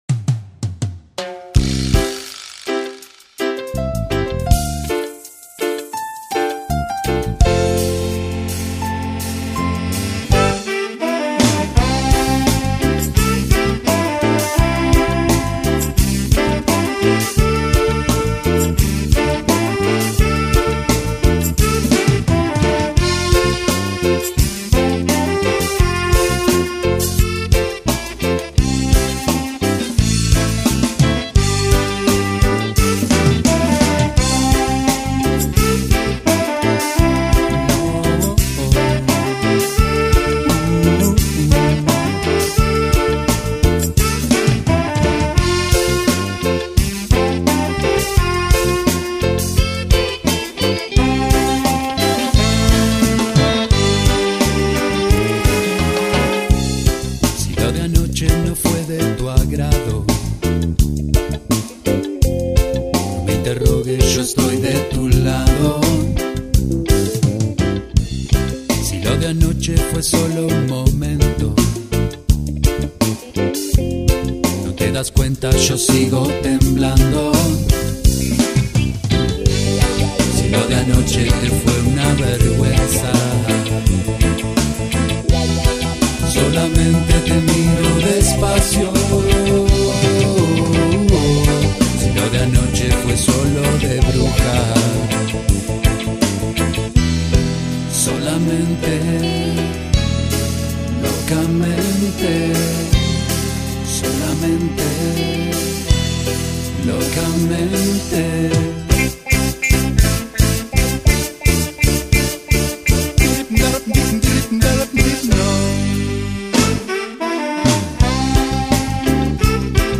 Demo grabado entre enero y abril del 2001
Voz, coros y percusión
Bajo, coros, teclados y percusión
Guitarra y coros
Primera guitarra, guitarra española y coros
Batería, teclado, percusión y coros
Saxos (tenor y alto)
saxo alto